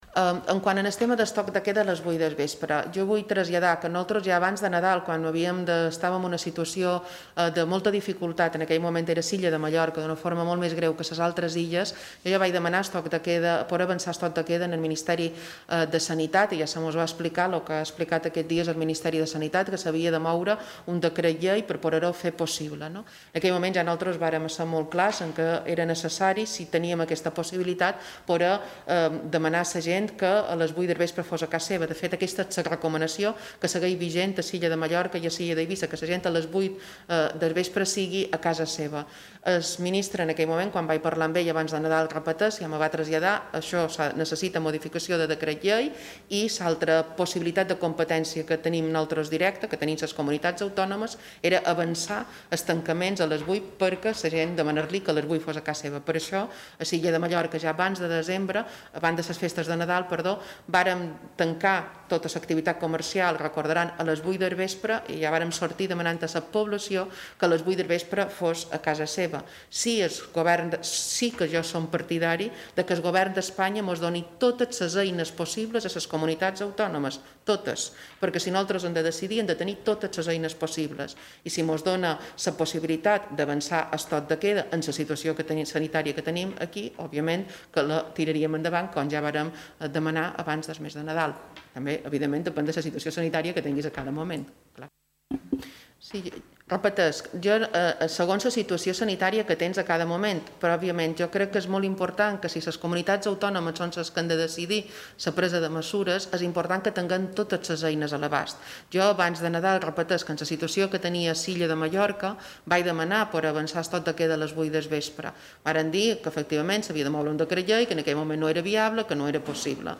En declaracions als mitjans de comunicació a Menorca, la presidenta ha reclamat a l’executiu de Pedro Sánchez que doni “totes les eines possibles a les comunitats”.
Podeu escoltar aquí les declaracions de Francina Armengol: